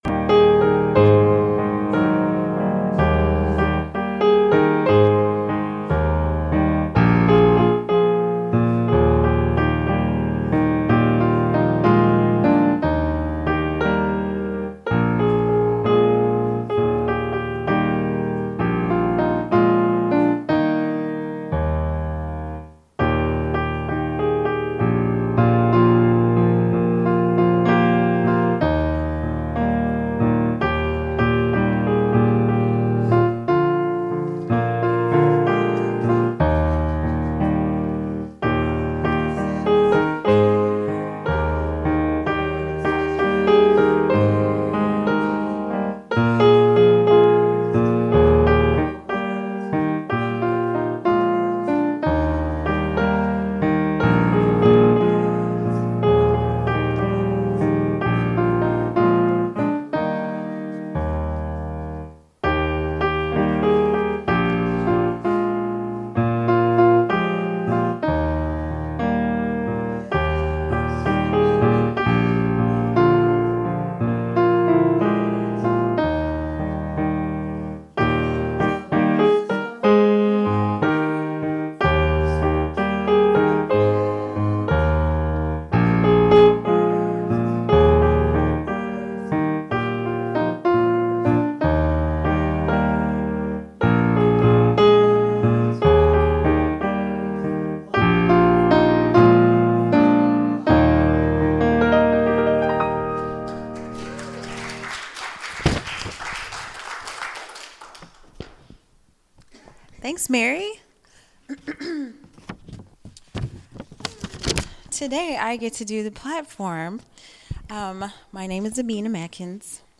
Spiritual Leader Series: Sermons 2021 Date